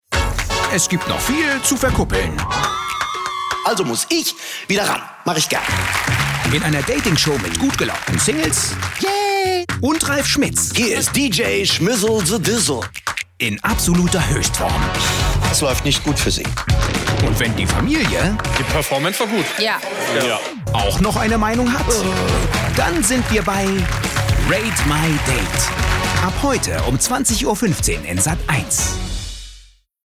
dunkel, sonor, souverän, markant
Station Voice